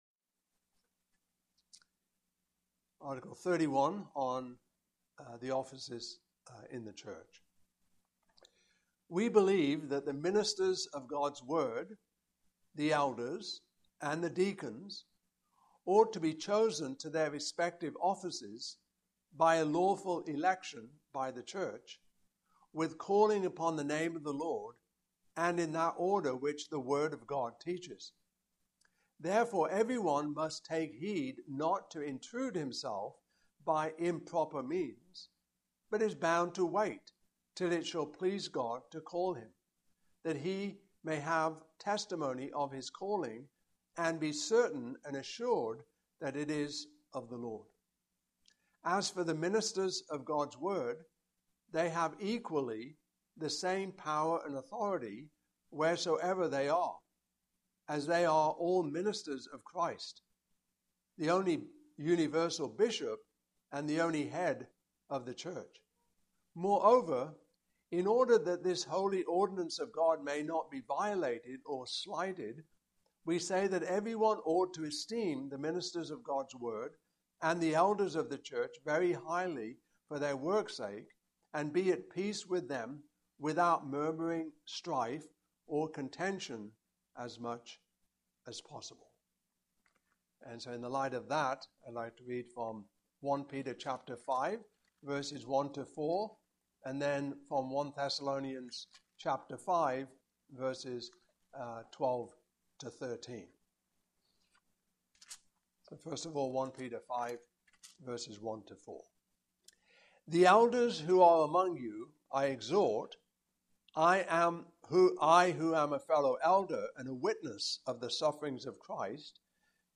Belgic Confession 2025 Passage: 1 Thessalonians 5:12-13 Service Type: Evening Service